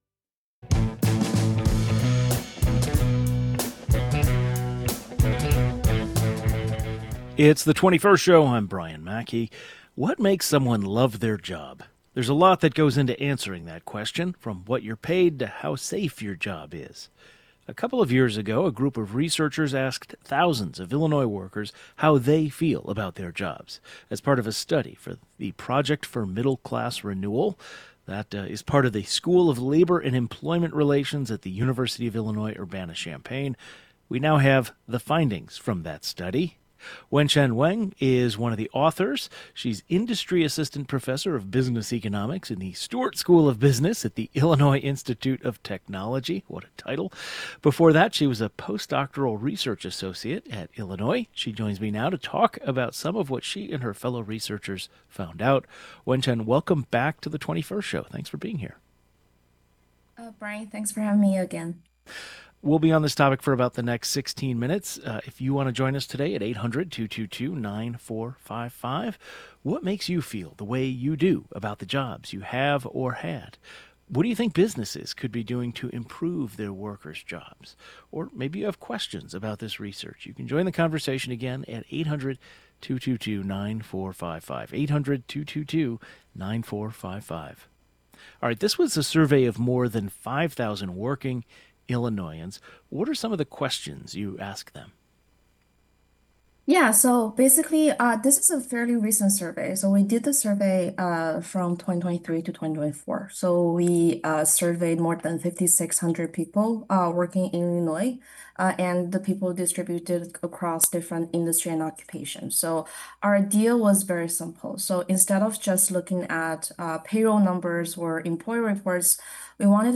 The 21st Show is Illinois' statewide weekday public radio talk show, connecting Illinois and bringing you the news, culture, and stories that matter to the 21st state.
One of the authors of the study joins the program to discuss what the team learned.